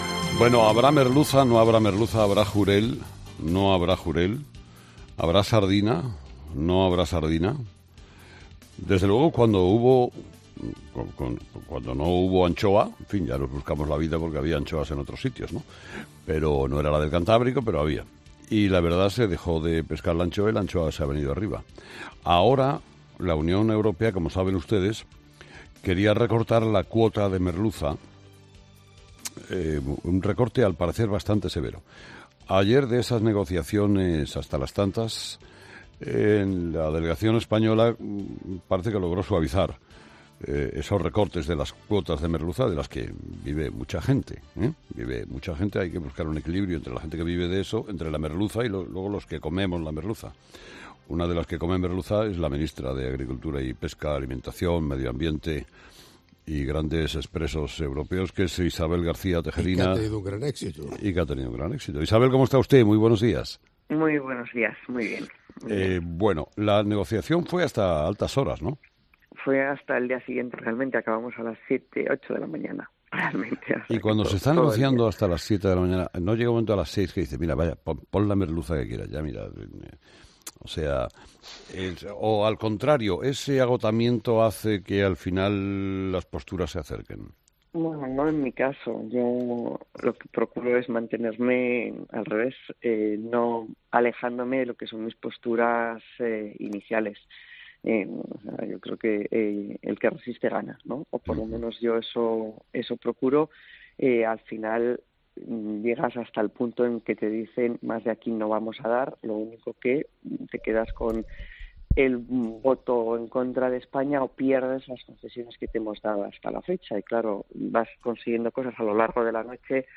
ESCUCHA LA ENTREVISTA COMPLETA | Isabel García Tejerina en 'Herrera en COPE'